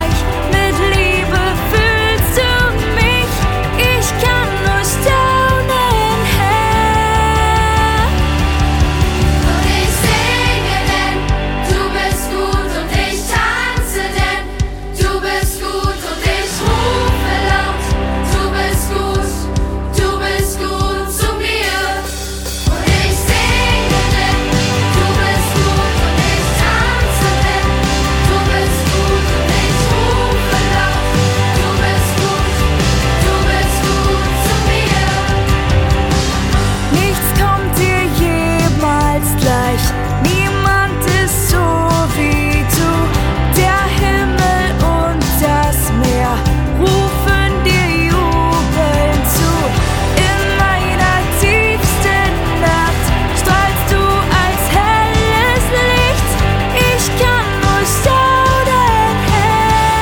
Akustik Version